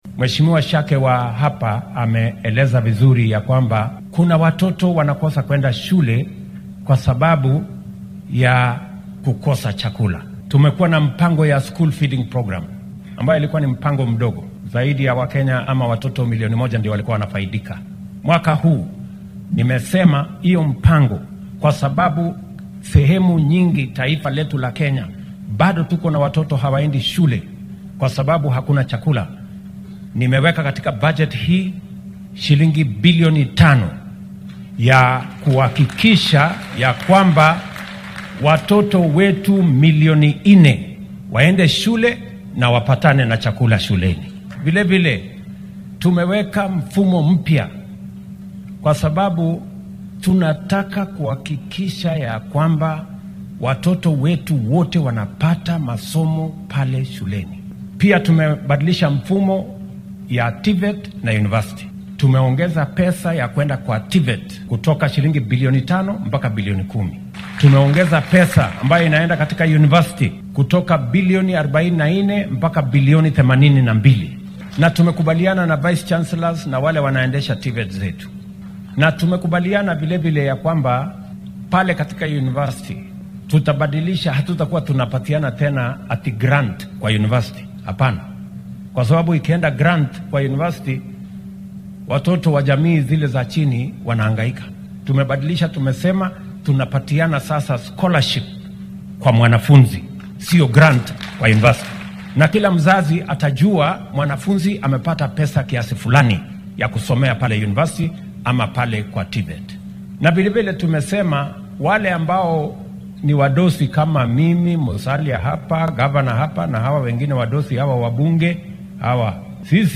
Munaasabadda uu madaxweyne Ruto ka qayb galaya ayaa ka socoto fagaaraha Mwatunge grounds ee magaalada Mwatate ee ismaamulka Taita Taveta.
Madaxweynaha dalka William Ruto oo ka hadlay arrimaha waxbarashada ayaa sheegay in sanadkan barnaamijka cunnada ee ardayda loo qoondeeyay 5 bilyan oo shilin si carruur badan ay dugsiyada ugu biiraan.